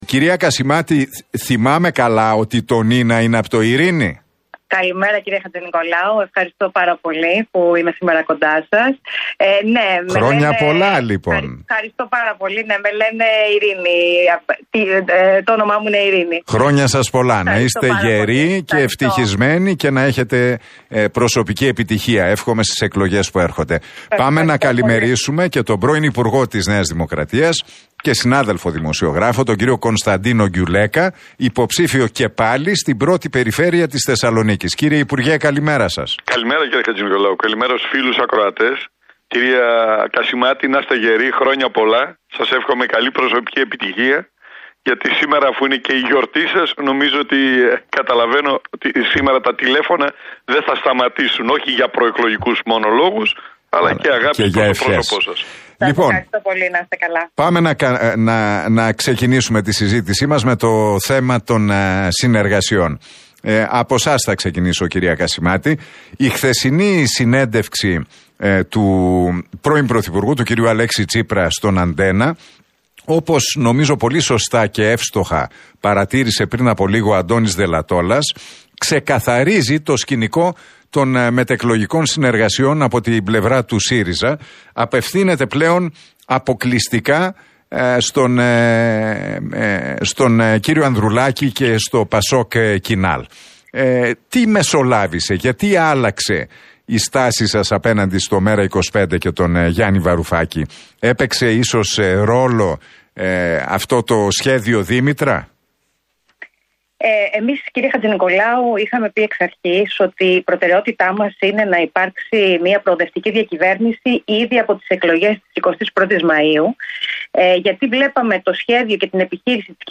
Εκλογές 2023 – Debate Γκιουλέκα - Κασιμάτη στον Realfm 97,8
Τα ξίφη τους διασταύρωσαν στον αέρα του Realfm 97,8 και την εκπομπή του Νίκου Χατζηνικολάου, σε ένα debate ο υποψήφιος με τη ΝΔ, Κωνσταντίνος Γκιουλέκας και η